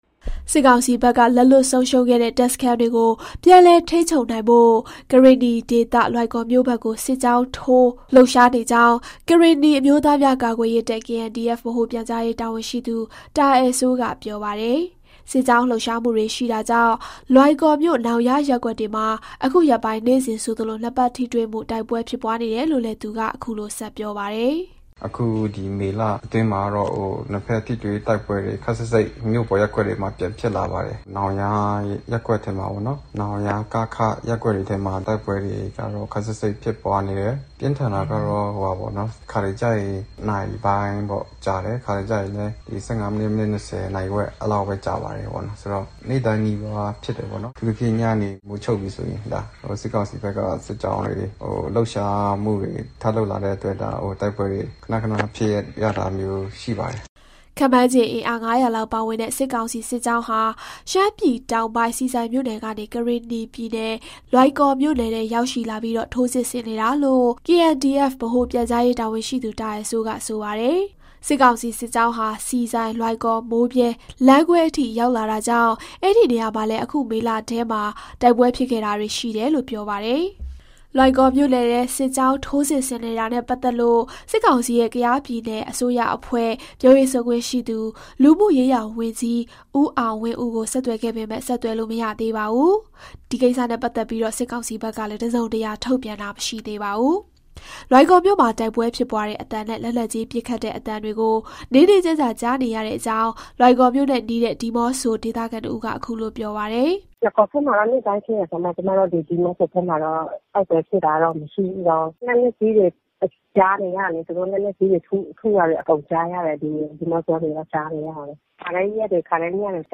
လွိုင်ကော်မြို့မှာ တိုက်ပွဲဖြစ်ပွားတဲ့ အသံနဲ့ လက်နက်ကြီးပစ်ခတ်တဲ့အသံတွေ နေ့နေ့ညည ကြားနေရတဲ့အကြောင်း လွိုင်ကော်မြို့နဲ့ နီးတဲ့ ဒီးမော့ဆိုဒေသခံတဦးက ခုလို ပြောပါတယ်။